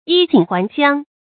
yì jǐn huán xiāng
衣锦还乡发音
成语正音 衣；不能读做“yī”；还，不能读作“hái”。